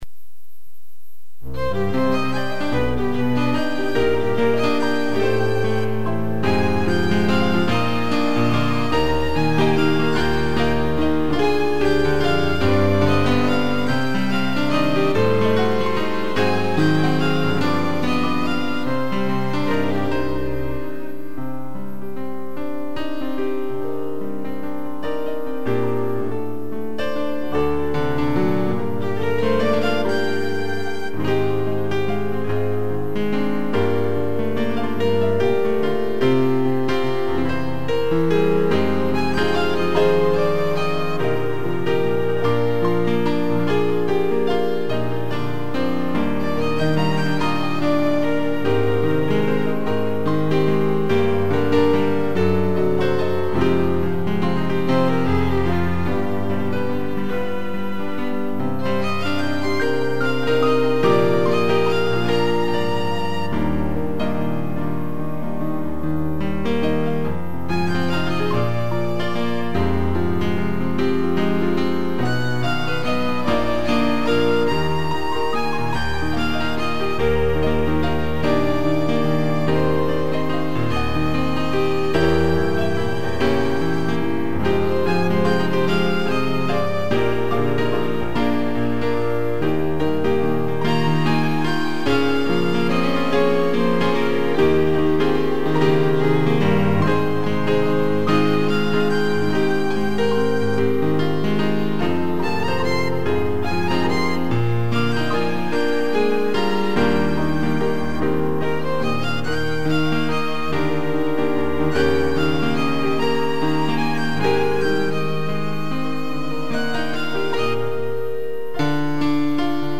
2 pianos, violino e cello